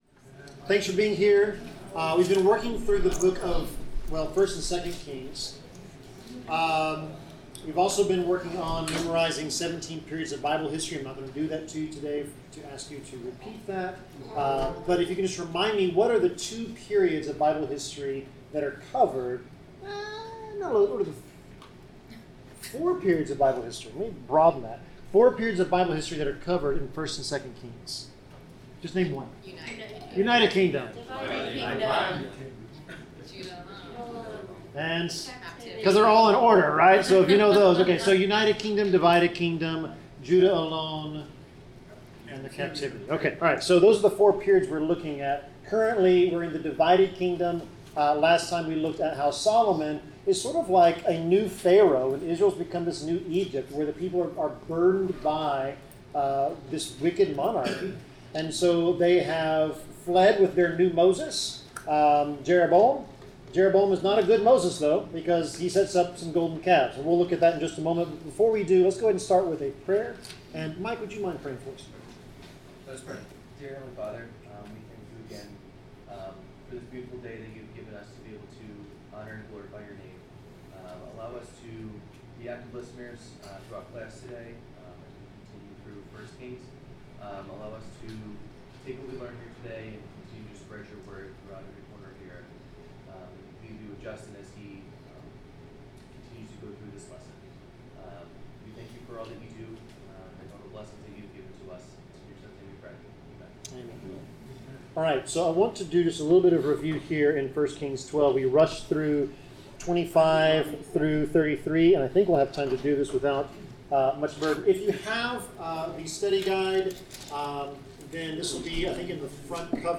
Bible class: 1 Kings 12-13 (Dan & Bethel and The Man of God from Judah)
Service Type: Bible Class